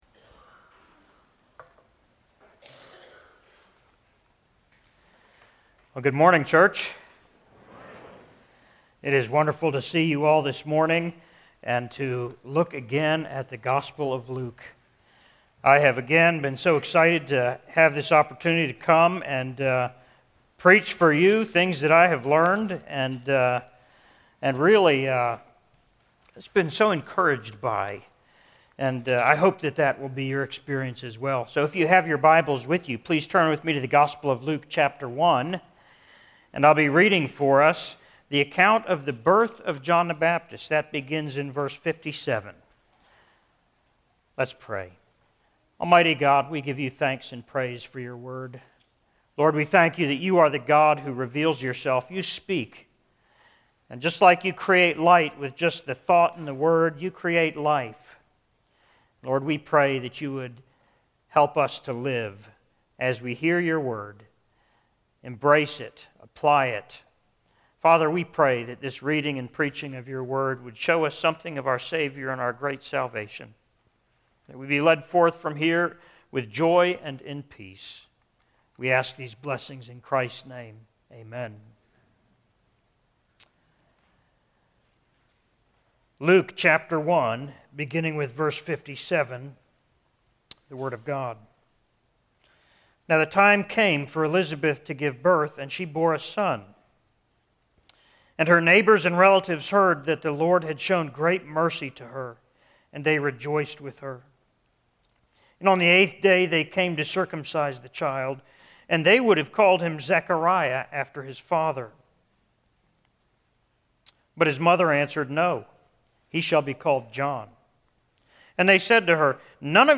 Sermon on Luke 1:57-66: Special Effects - Columbia Presbyterian Church